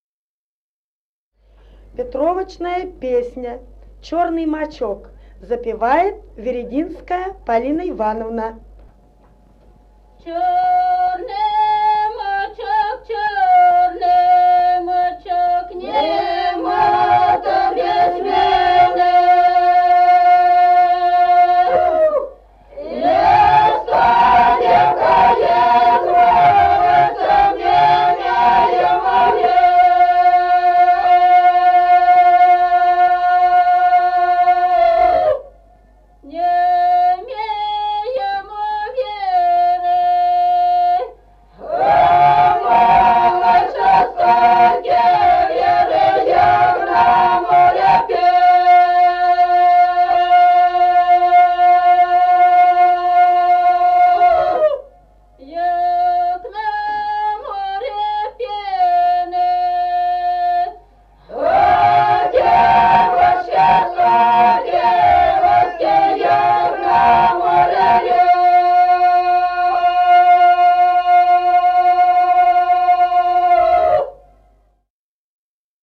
Песни села Остроглядово. Чёрный мачок.